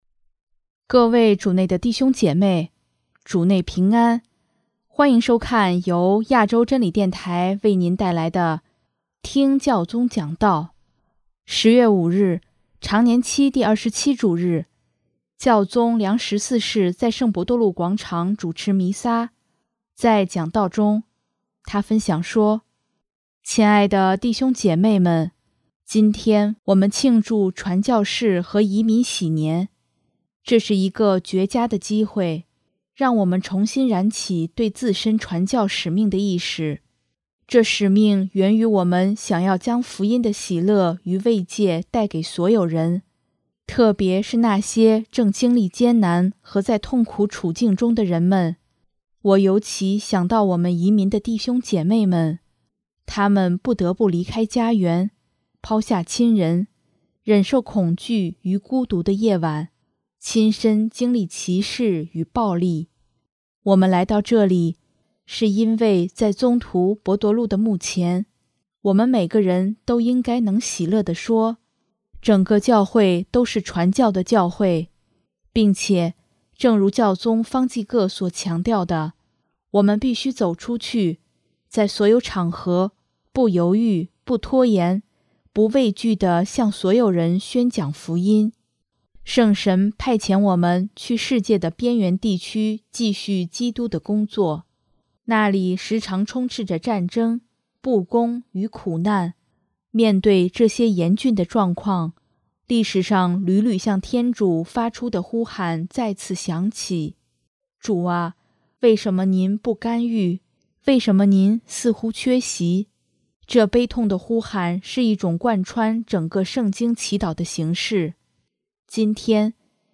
首页 / 新闻/ 听教宗讲道
10月5日，常年期第二十七主日，教宗良十四世在圣伯多禄广场主持弥撒，在讲道中，他分享说：